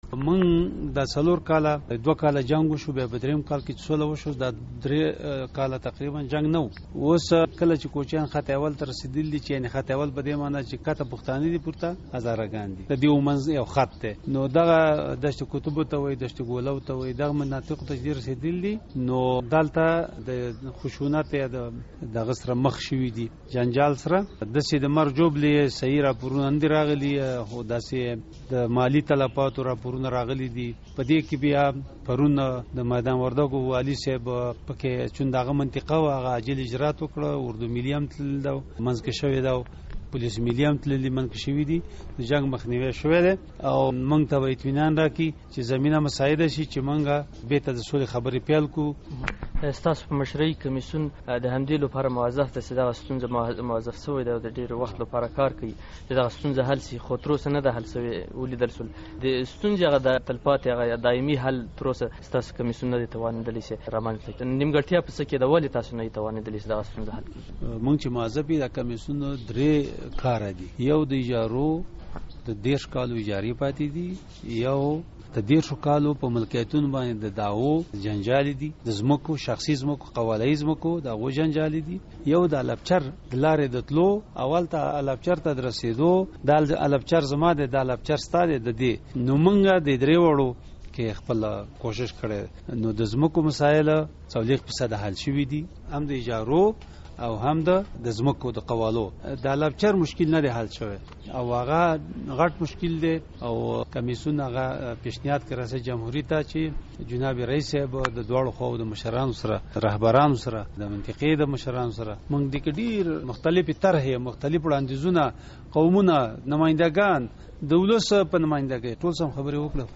له وحيدالله سباوون سره مركه